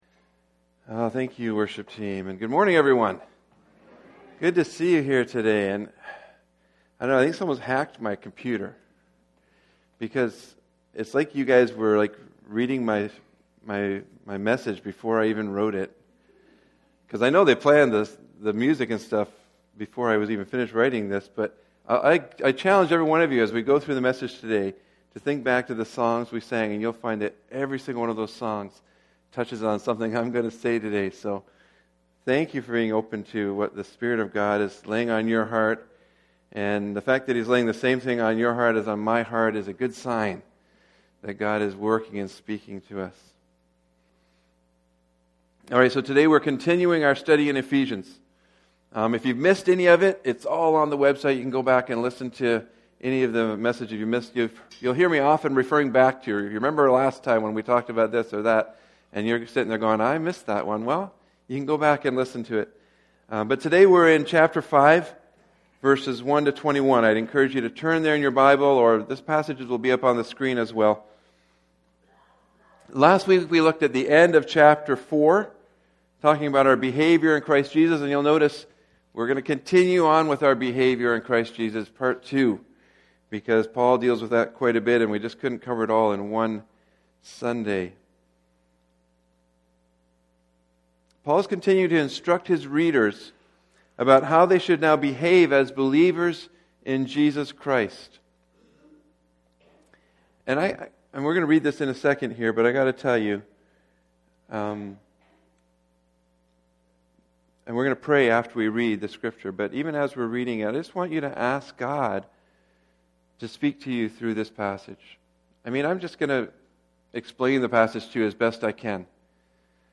Sermons | Ritson Road Alliance Church
Guest Speaker